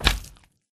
mob / magmacube / big1.ogg